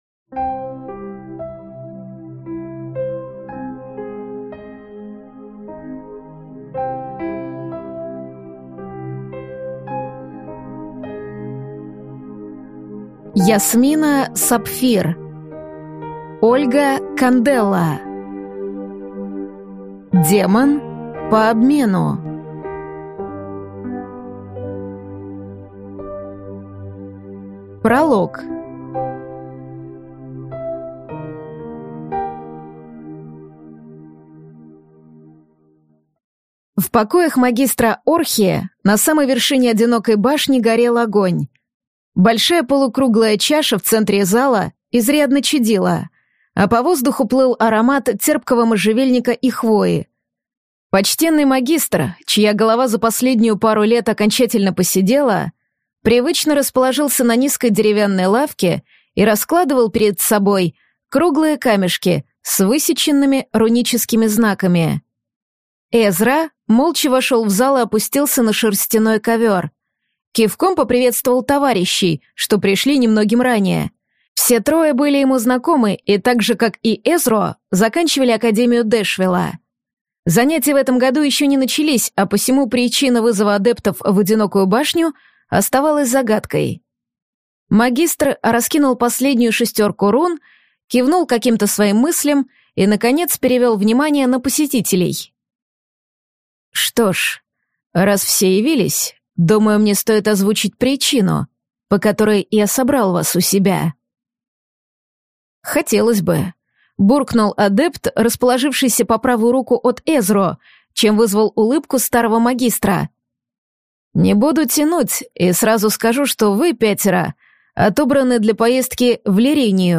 Аудиокнига Демон по обмену | Библиотека аудиокниг
Прослушать и бесплатно скачать фрагмент аудиокниги